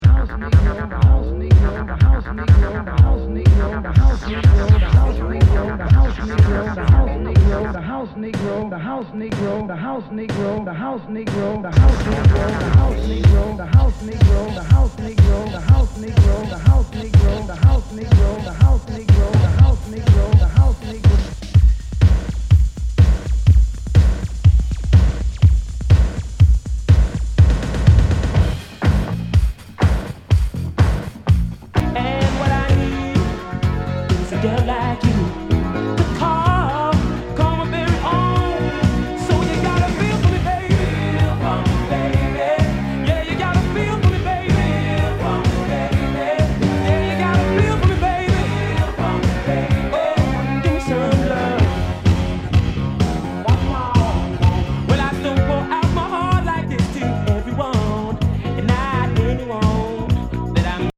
HOUSE/TECHNO/ELECTRO
ナイス！アシッド / ユーロ・ヴォーカル・ハウス！
全体にチリノイズが入ります